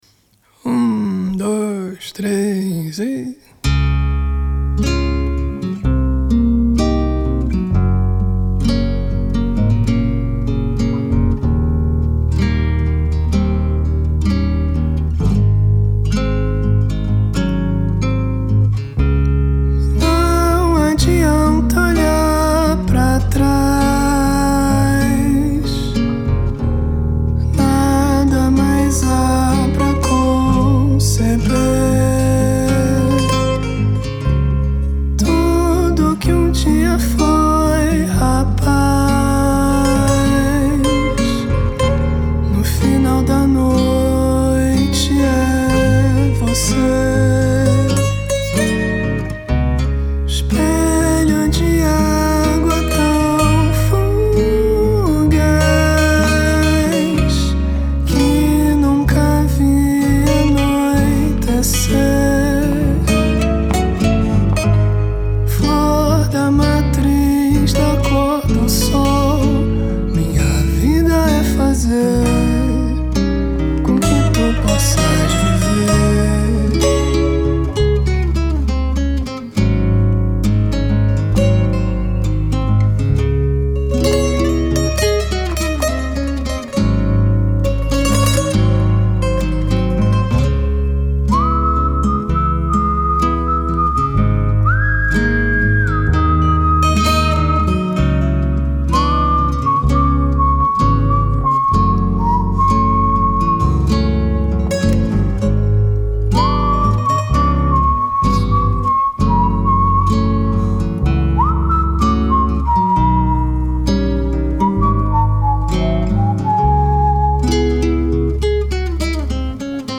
guitarra elétrica
guitarra braguesa
baixo
bateria